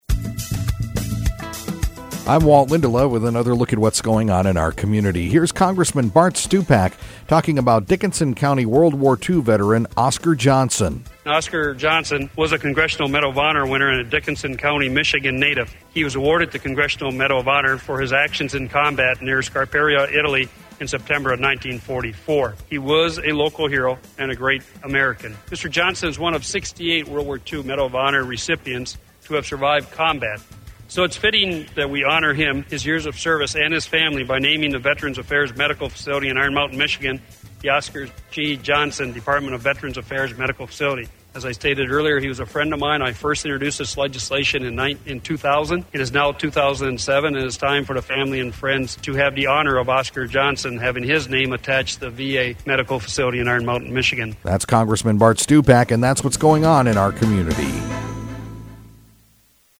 INTERVIEW: Congressman Bart Stupak